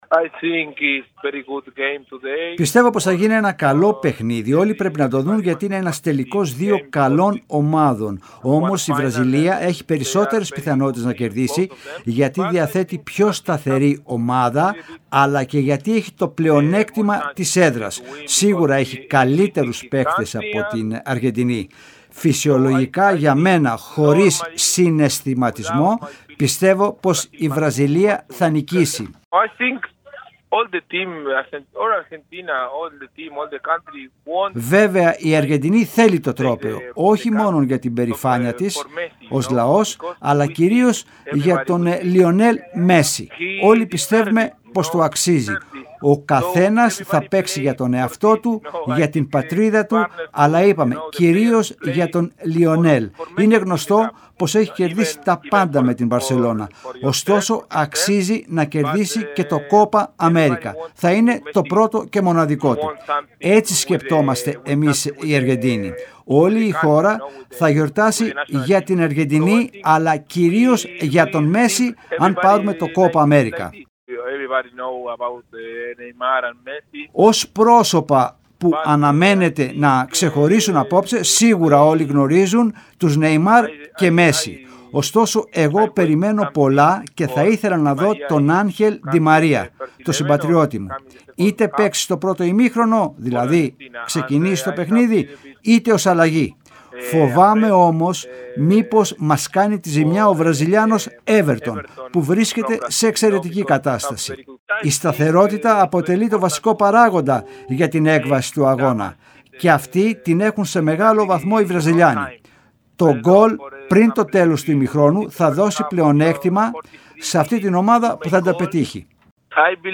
μιλώντας στον «Real fm 97,8» και εκπομπή Real Sports